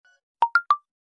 24. bongo